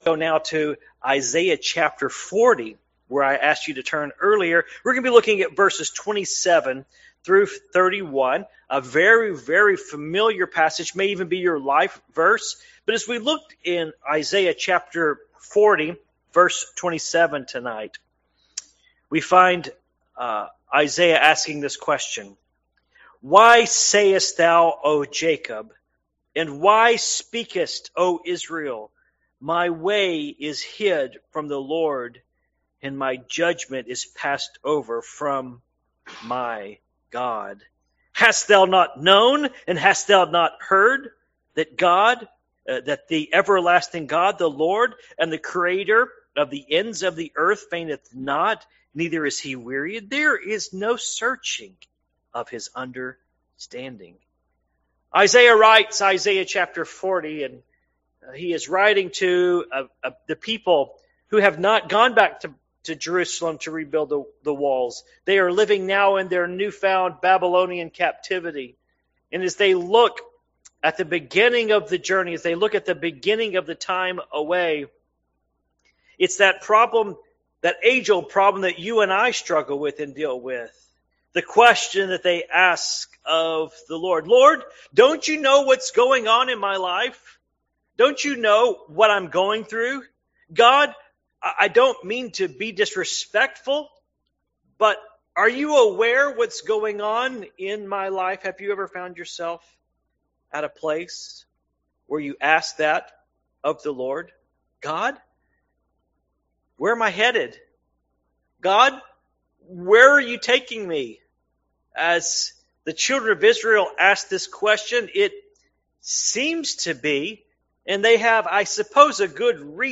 Passage: Isaiah 40:27-31 Service Type: Evening Worship